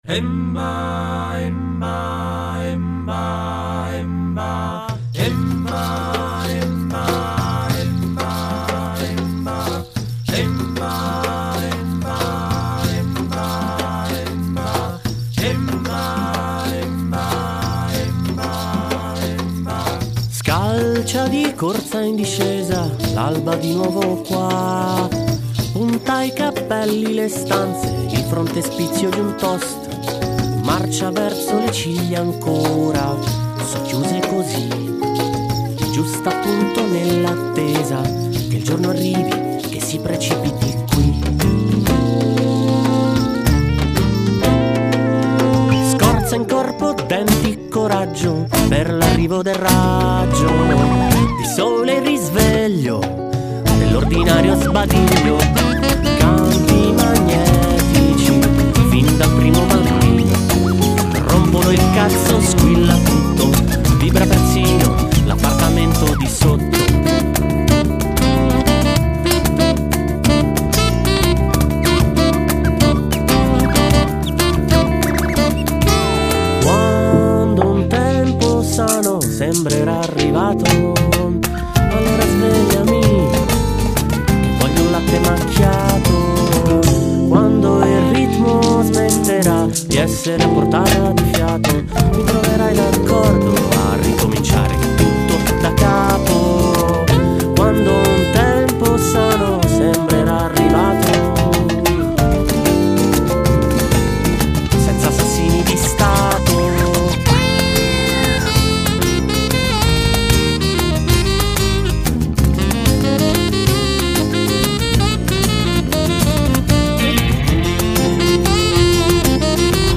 Description Limboskata Bat?, Cajon, Congas, effetti vari....